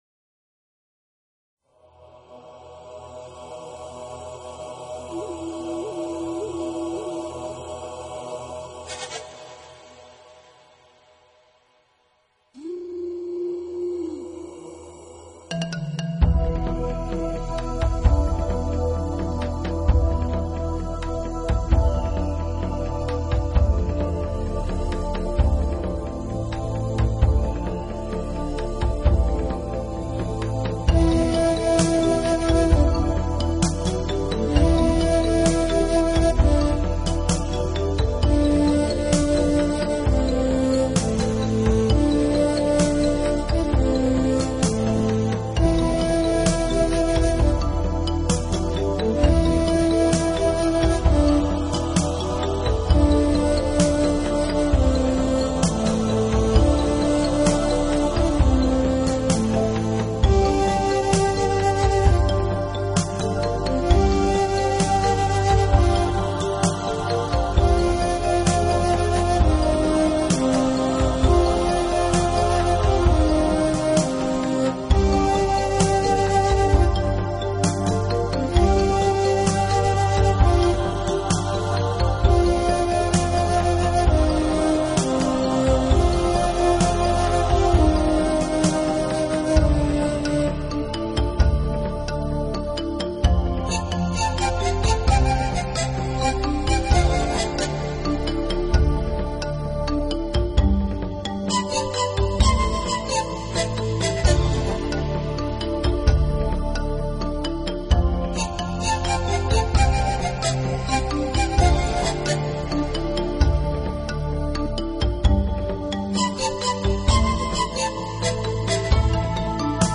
音乐类型：NewAge 新世纪
音乐充满活力，排萧和鼓乐结合的力量是巨大的，魔幻的虚空感觉回绕在我们的心头。
表达出一种欢跃的心情和氛围。而打击乐器从头到尾始终伴随。